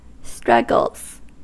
Ääntäminen
Ääntäminen US Haettu sana löytyi näillä lähdekielillä: englanti Käännöksiä ei löytynyt valitulle kohdekielelle. Struggles on sanan struggle monikko.